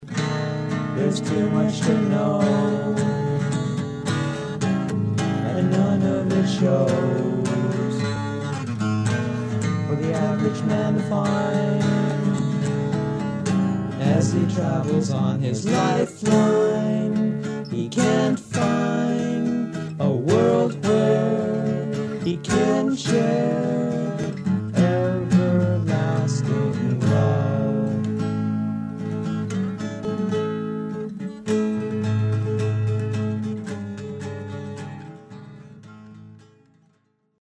(original demo)